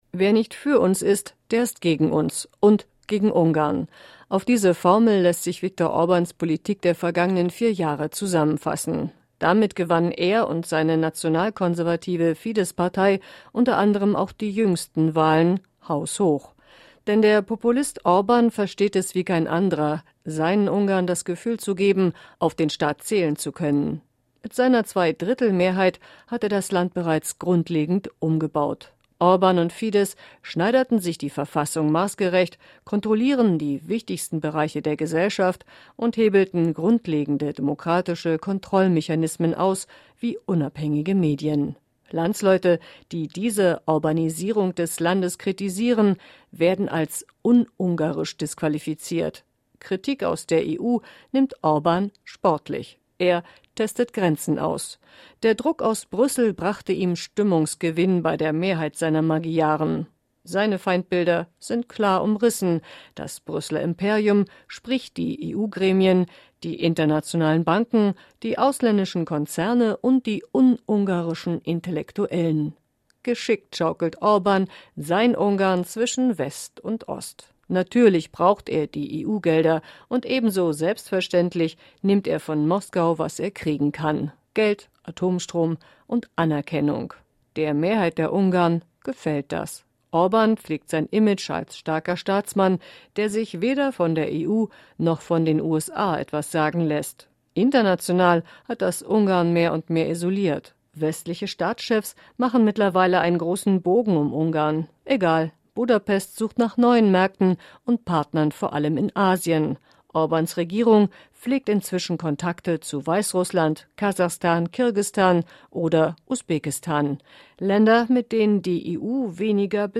Ein Audio-Kommentar